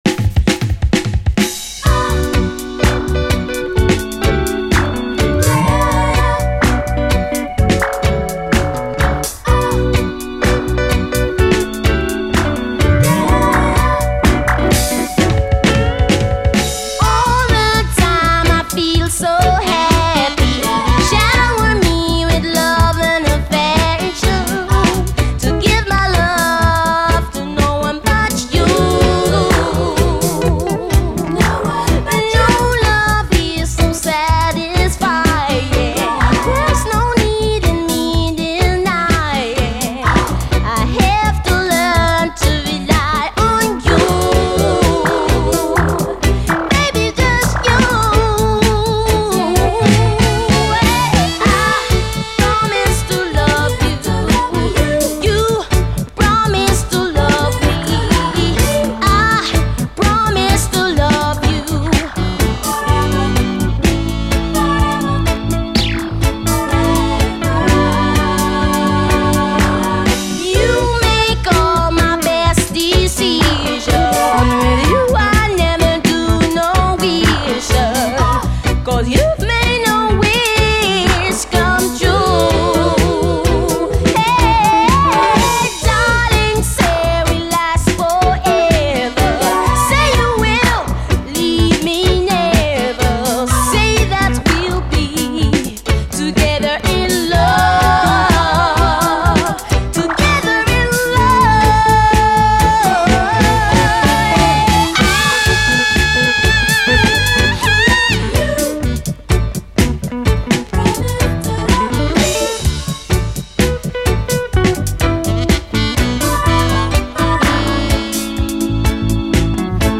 REGGAE
後半はダブに接続。